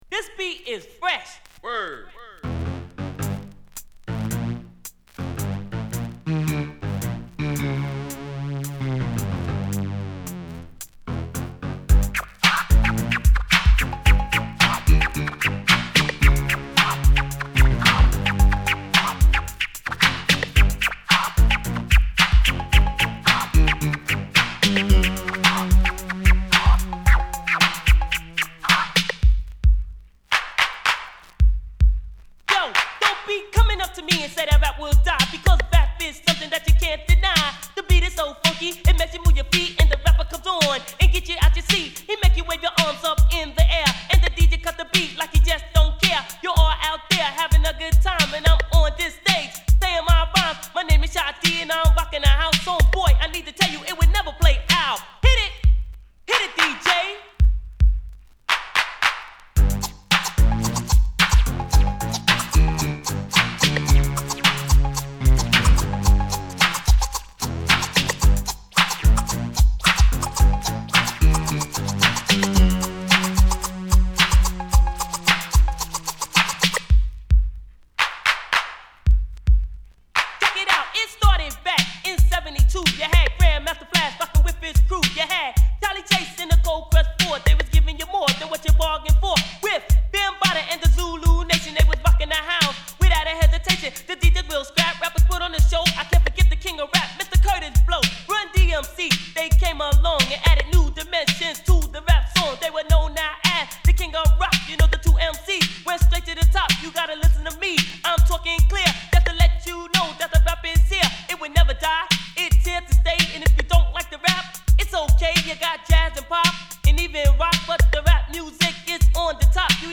小気味良いマシーンドラムに